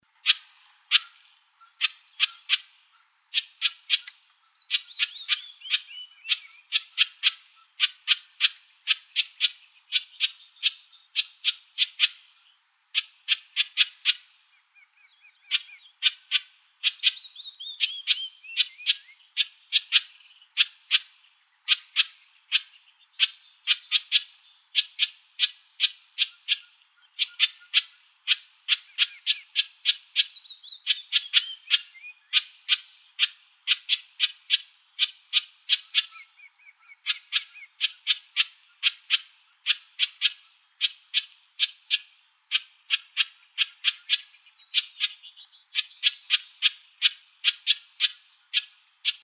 Housewren.wav